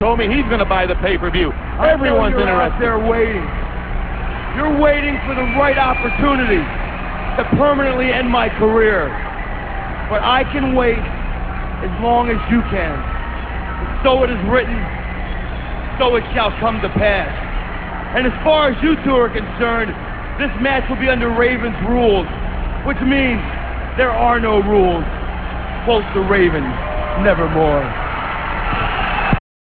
- This speech comes from WCW Thunder - [6.24.98]. Raven talks about how he can wait for Kanyon as long as it takes and tells TPE their match is under Raven's rules.